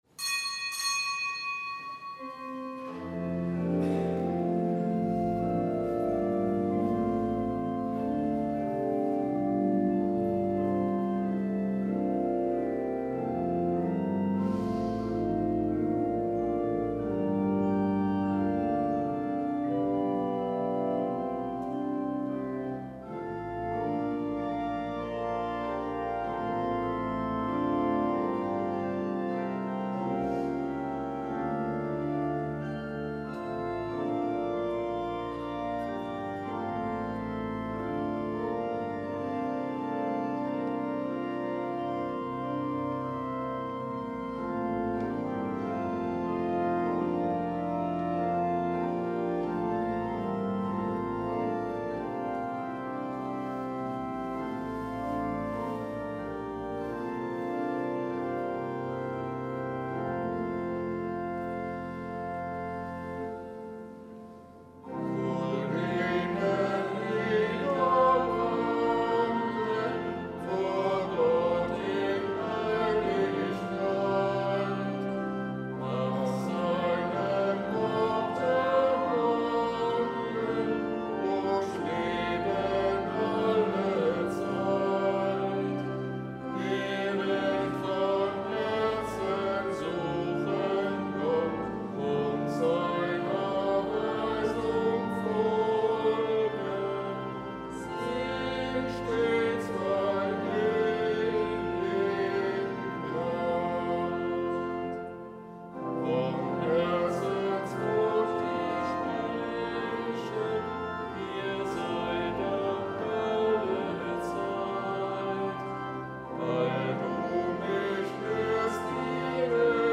Kapitelsmesse am Samstag der vierzehnten Woche im Jahreskreis
14.07.2024 Kapitelsmesse am Samstag der vierzehnten Woche im Jahreskreis © PICTOR PICTURES ( (Link ist extern) shutterstock ) Drucken Get an embed code Share on Facebook Twitter Herunterladen Kapitelsmesse aus dem Kölner Dom am Samstag der vierzehnten Woche im Jahreskreis, Nichtgebotener Gedenktag des Heiligen Heinrich II. und der Heiligen Kunigunde, Kaiserpaar (RK, GK: Hl. Heinrich II.)